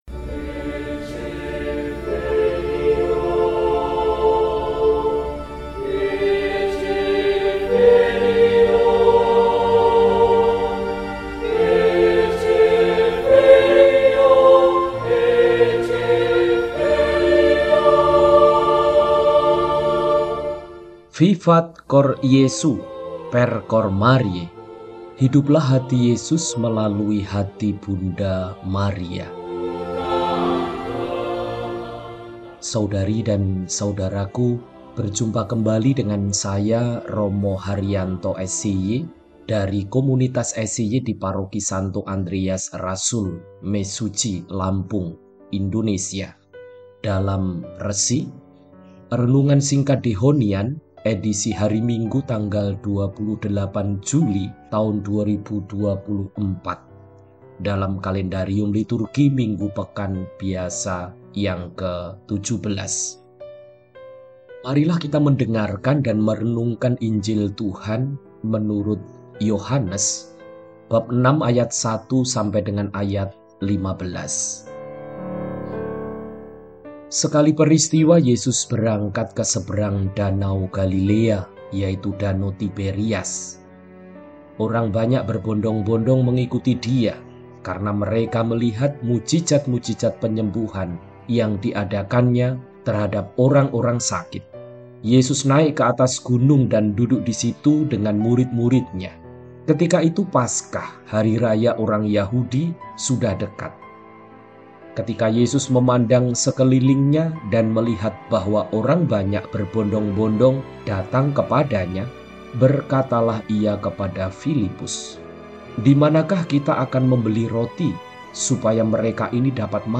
Minggu, 28 Juli 2024 – Hari Minggu Biasa XVII – RESI (Renungan Singkat) DEHONIAN